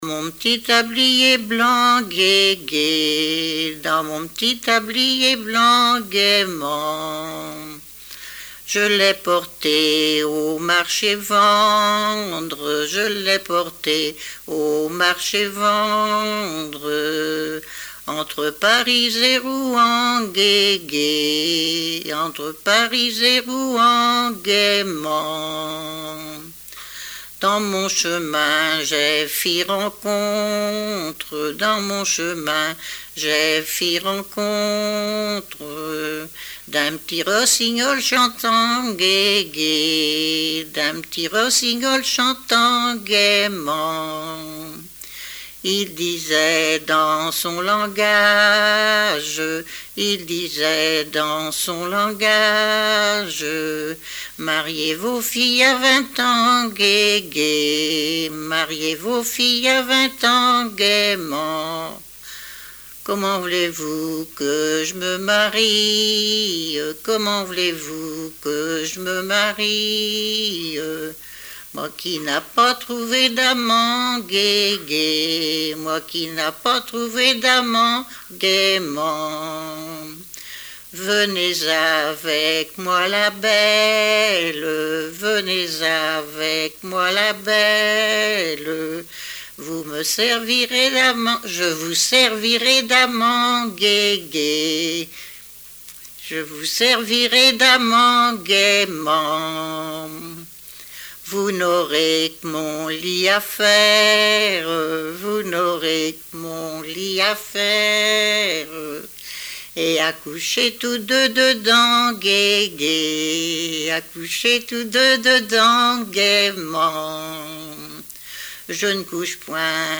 Genre laisse
Répertoire de chansons populaires et traditionnelles
Pièce musicale inédite